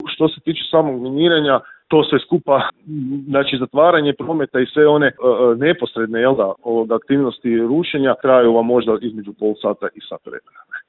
razgovarali smo u Intervjuu Media servisa